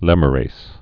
(lĕmə-rās, lĕmyə-rēz)